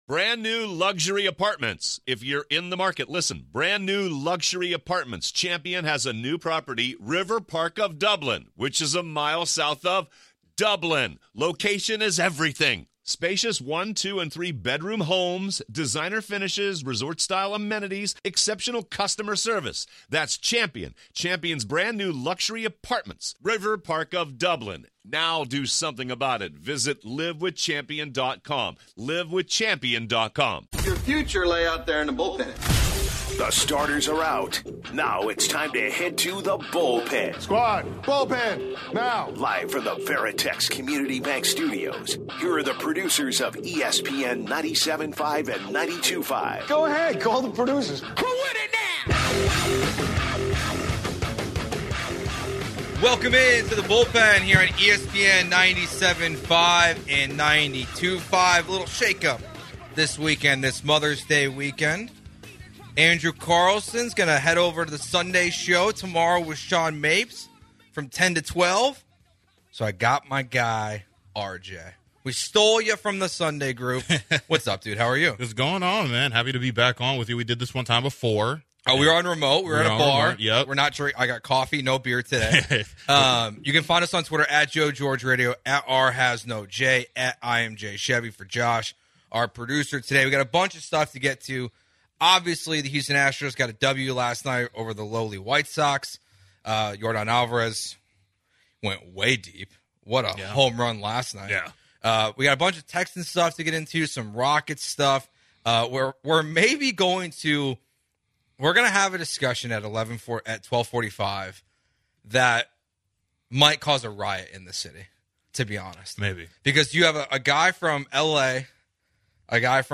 the two hosts cover the most recent Astro and Texan news during hour 1!